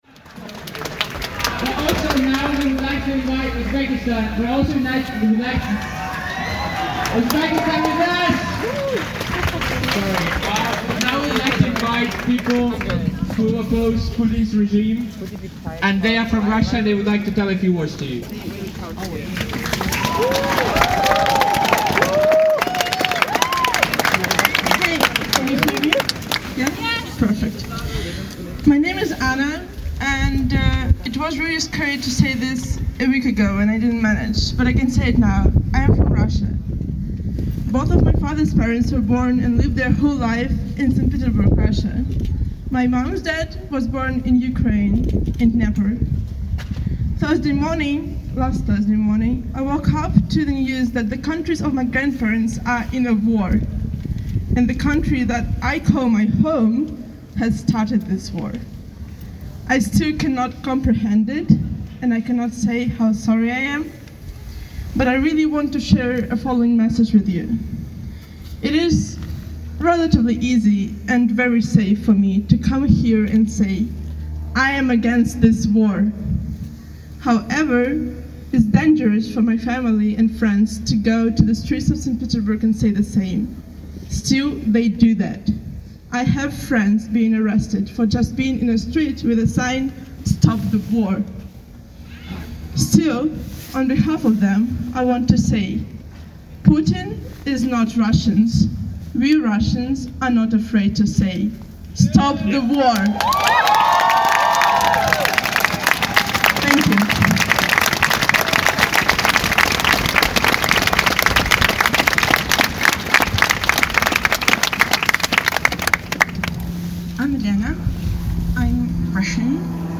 6 March 2022: A protest against the war in Ukraine held in Radcliffe Square, Oxford. Hundreds of people listen on and applaud in support of Russians speaking out against the war and Ukrainians speaking of their experience of the conflict. Three Russians tell us that they don't support Putin and the war, and a Ukrainian based in Oxford speaks of her experience and tells us about her family, still in the country.